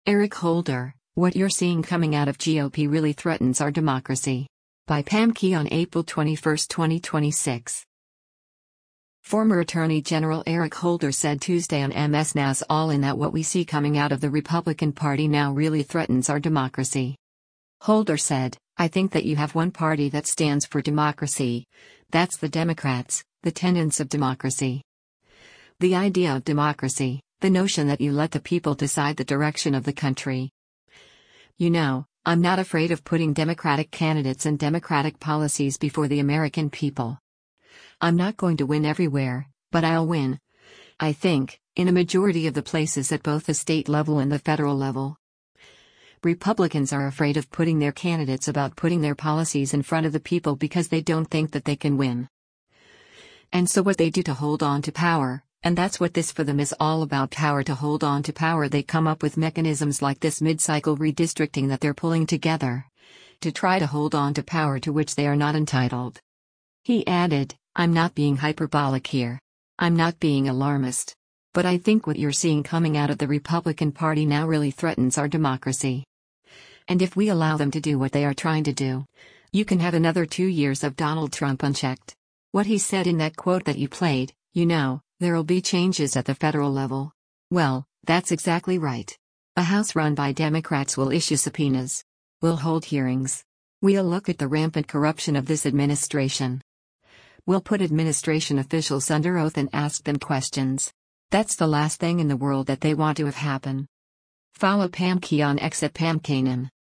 Former Attorney General Eric Holder said Tuesday on MS NOW’s “All In” that what we see “coming out of the Republican Party now really threatens our democracy.”